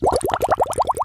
Bubbles